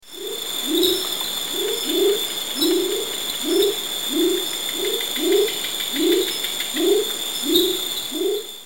دانلود صدای قورباغه در مرداب که حس شب و سکوت را تداعی می کند از ساعد نیوز با لینک مستقیم و کیفیت بالا
جلوه های صوتی